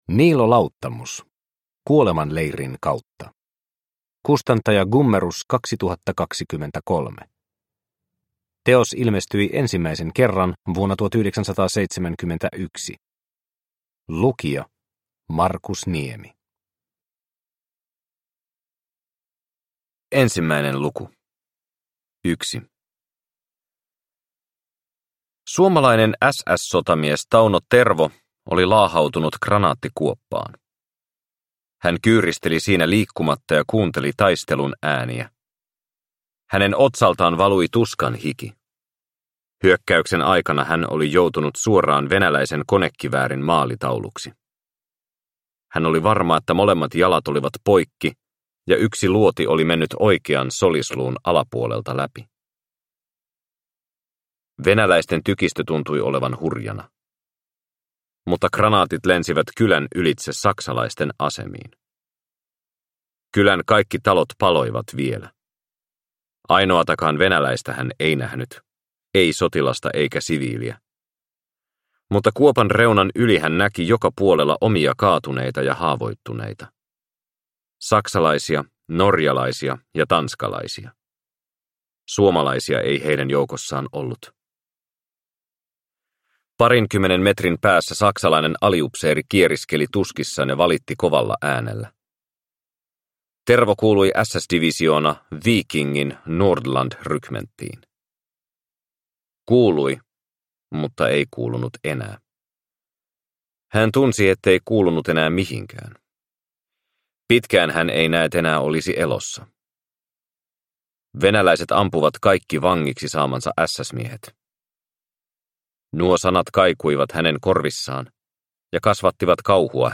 Kuolemanleirin kautta – Ljudbok – Laddas ner